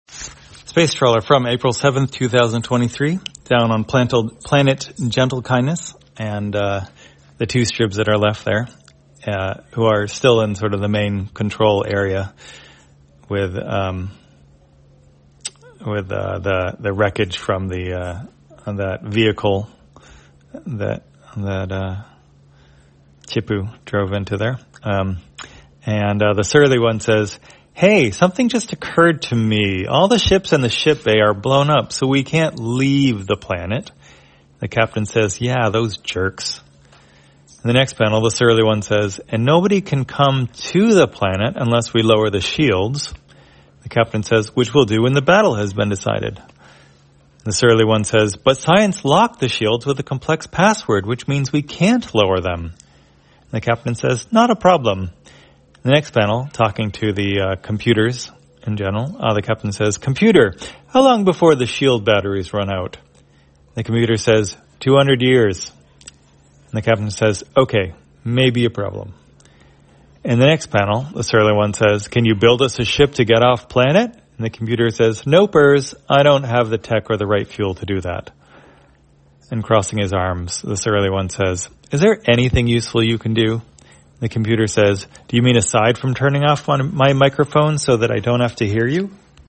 Spacetrawler, audio version For the blind or visually impaired, April 7, 2023.